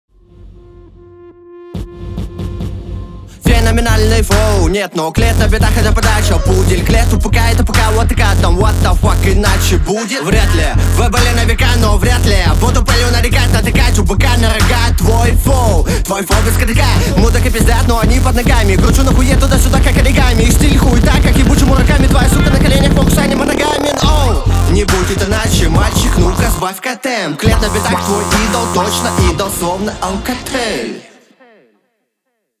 Текст стандартный, рифмовка очень простенькая, исполнение среднее, небезукоризненное.